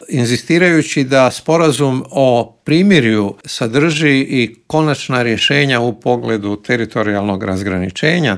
Na ta i ostala povezana pitanja u intervjuu Media servisa odgovorio je vanjskopolitički analitičar i bivši ambasador Hrvatske u Moskvi Božo Kovačević.